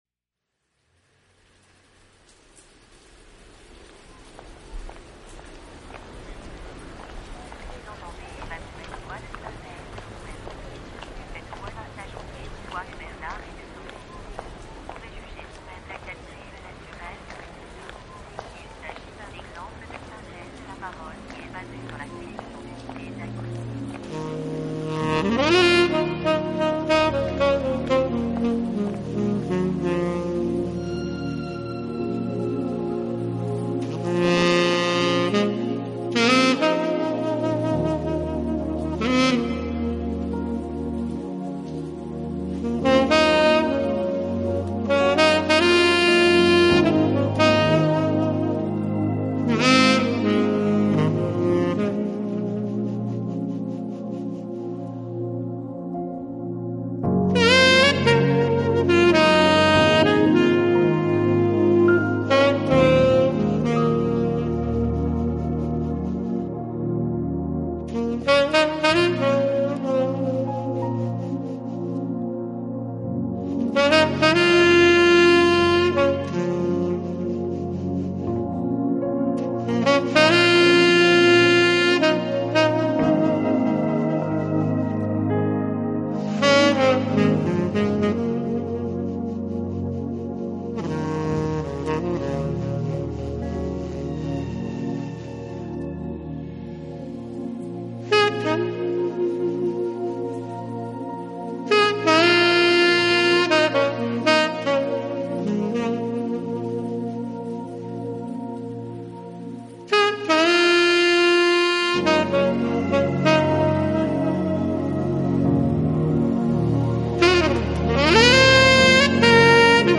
有着现代Jazz萨克斯风的演奏风格，
的爵士乐。
Smooth Jazz Sazphonist, 而他的音乐也被一致划分到现代爵士领域，但他却觉得自